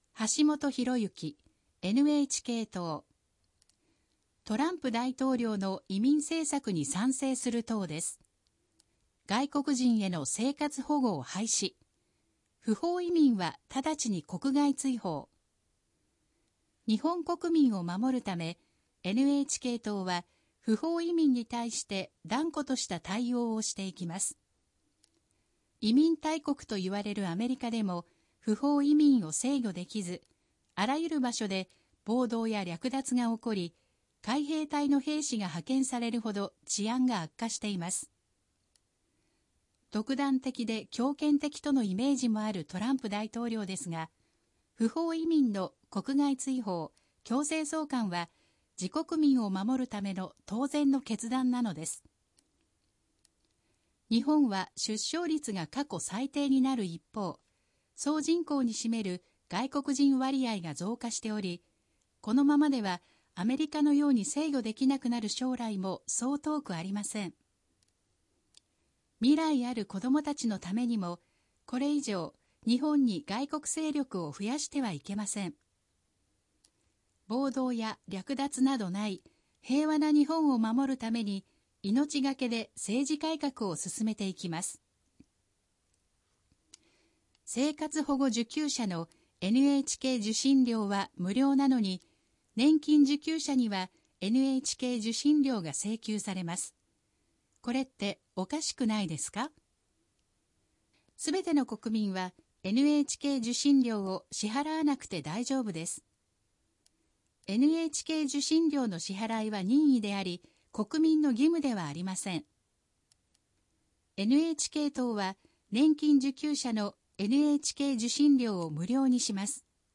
選挙公報の音声読み上げ対応データ（候補者提出）